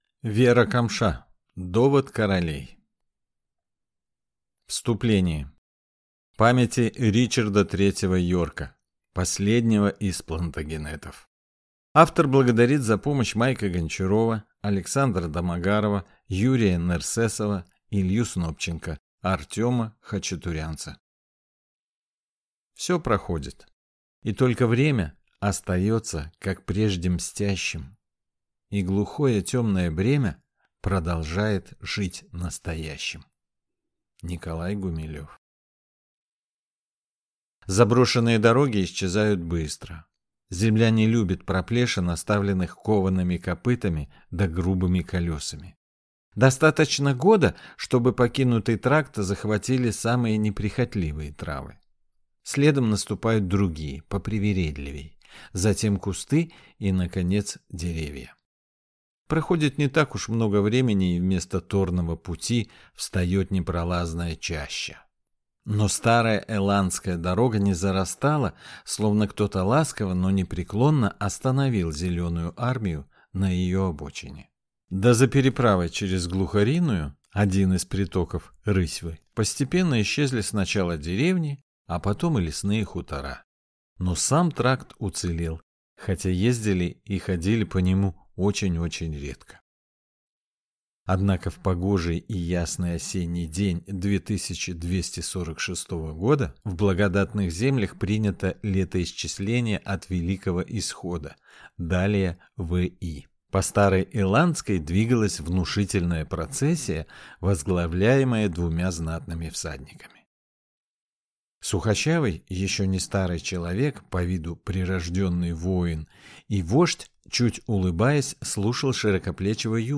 Аудиокнига Довод Королей | Библиотека аудиокниг